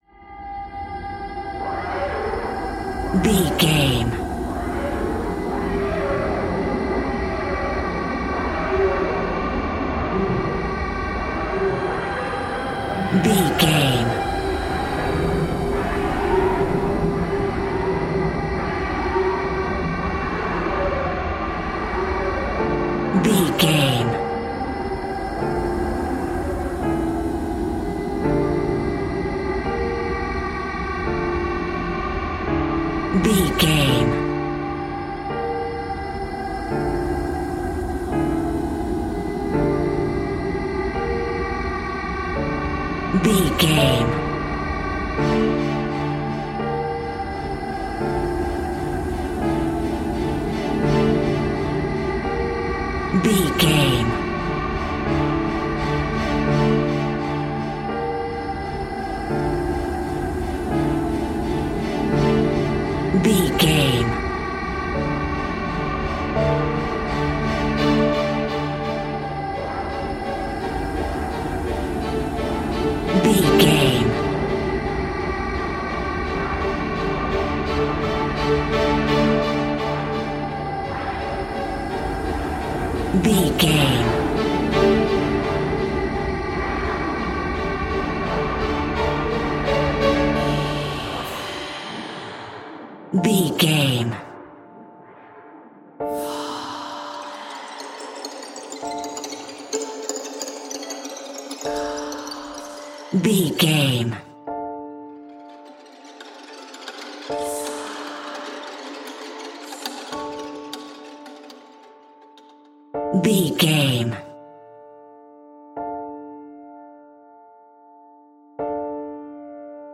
Aeolian/Minor
tension
ominous
dark
suspense
haunting
eerie
strings
synthesiser
ambience
pads